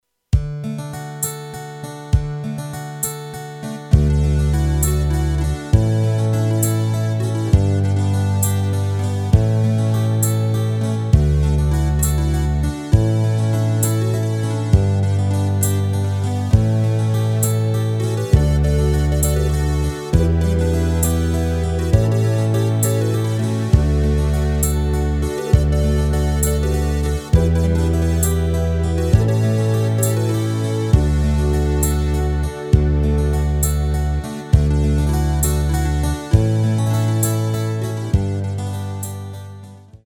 Rubrika: Folk, Country
HUDEBNÍ PODKLADY V AUDIO A VIDEO SOUBORECH